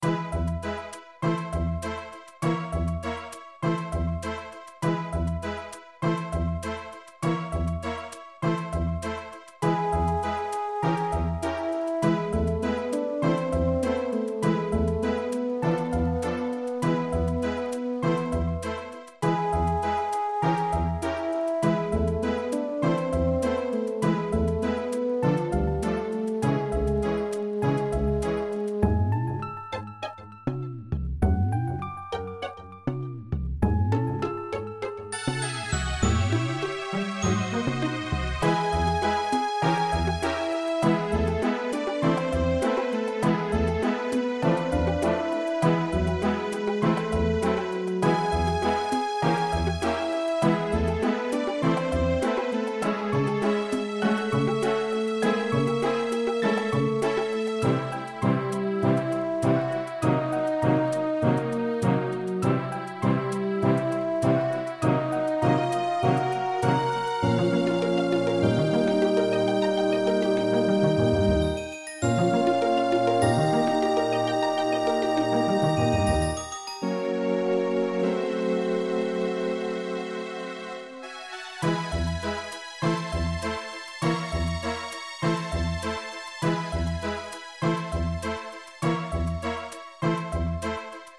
Application of EBU R 128 to all BGM